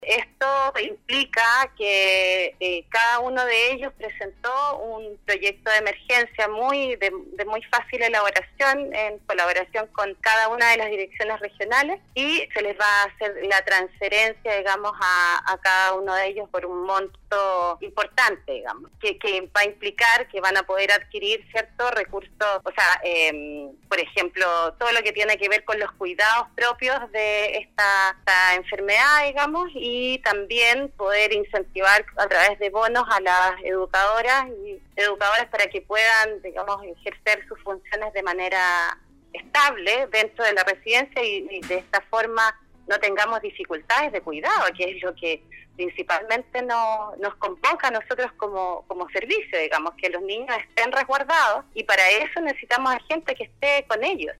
La mañana de este martes, Carolina Cortes Henríquez, directora del Servicio nacional de menores (SENAME) Atacama, sostuvo un contacto telefónico en el programa Al Día de Nostálgica donde explicó las acciones que se encuentran realizando para cuidar y resguardar la seguridad de los niños, niña y adolescente que atienden en la red Sename.